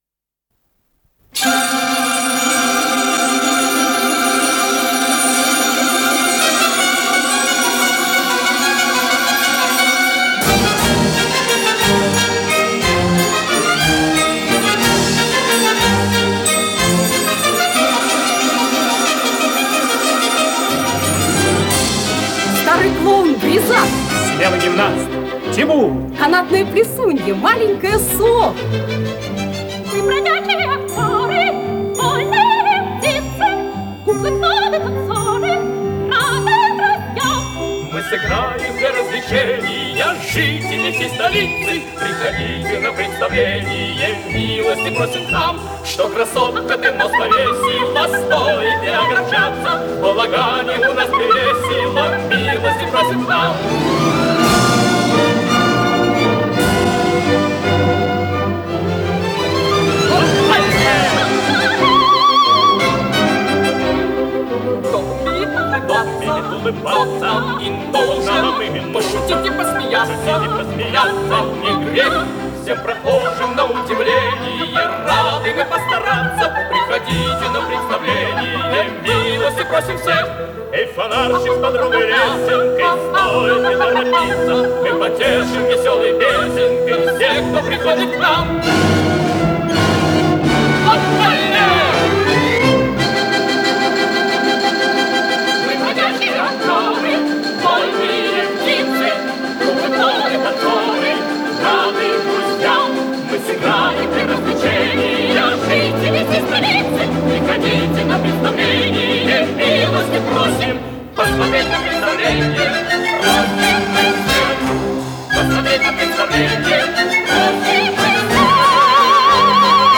с профессиональной магнитной ленты
сопрано
баритон
тенор
ВариантДубль моно